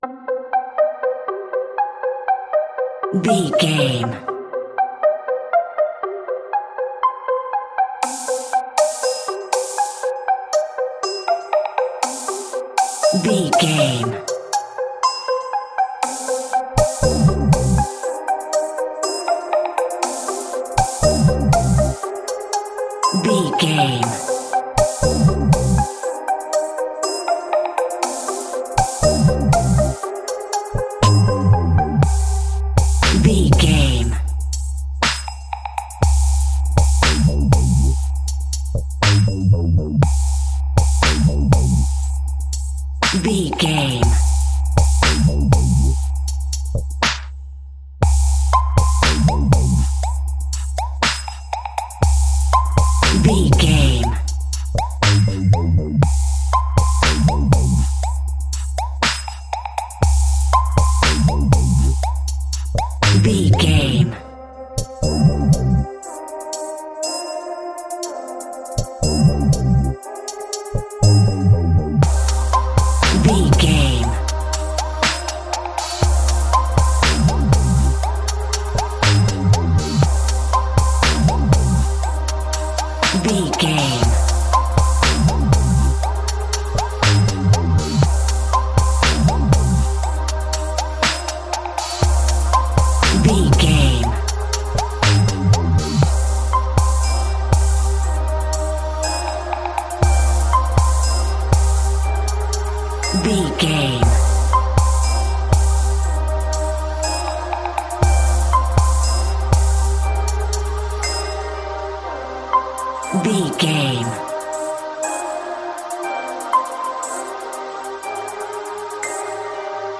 Aeolian/Minor
Slow
haunting
bouncy
electric piano
percussion